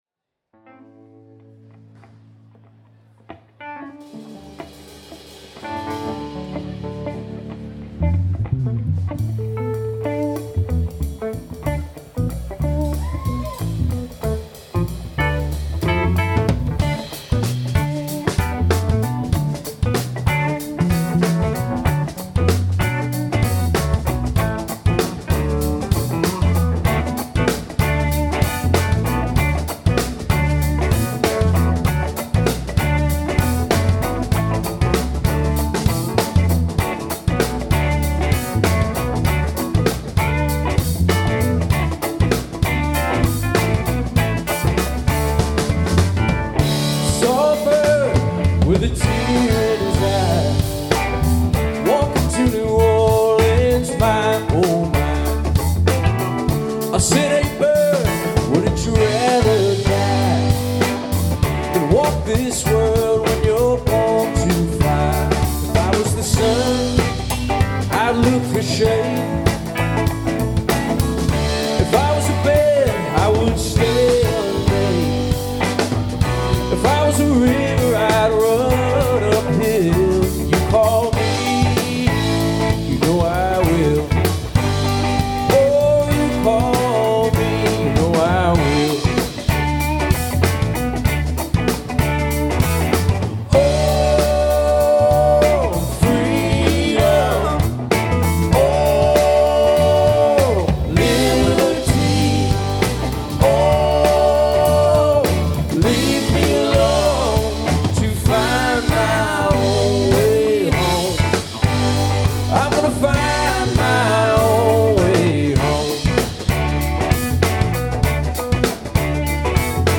Bass / Vox
Keys /Guitar / Vox
Guitar / Organ / Keys / Vox
Drums / Vox
They played two sets.